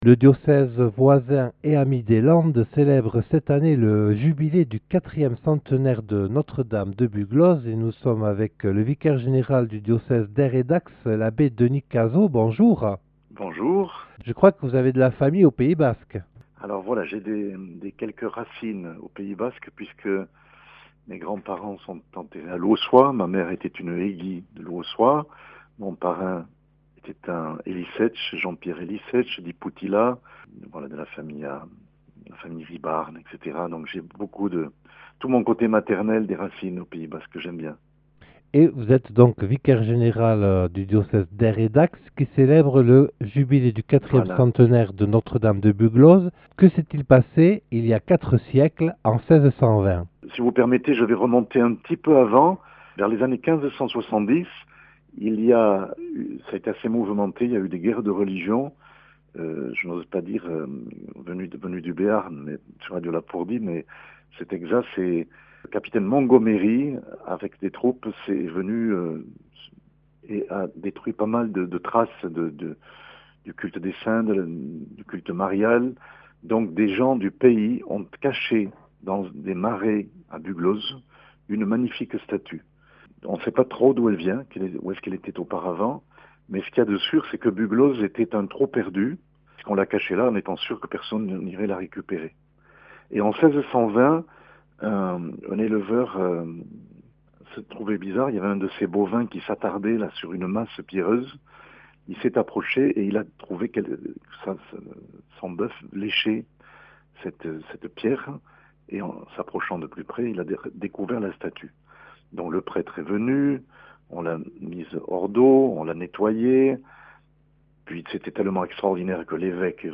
Accueil \ Emissions \ Infos \ Interviews et reportages \ Le Jubilé du 4ème centenaire de Notre-Dame de Buglose dans les (...)